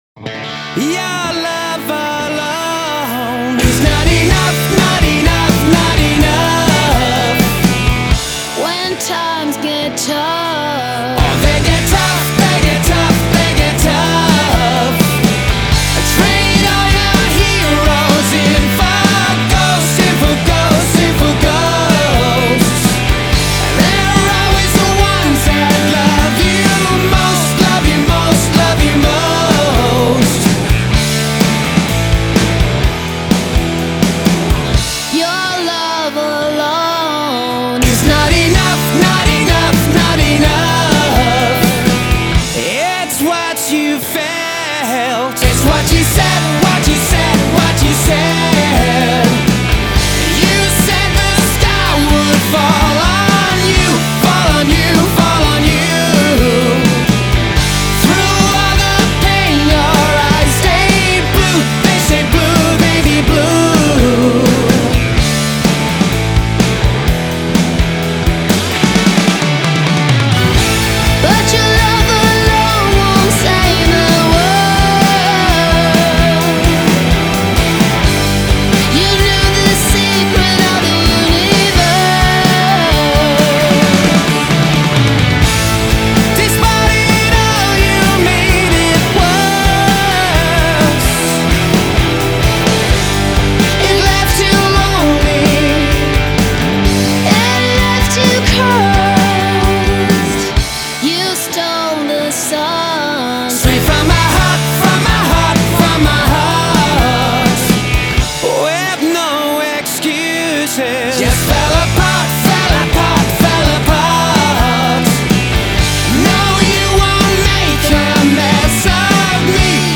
And then at the end a guitar solo of utter splendour.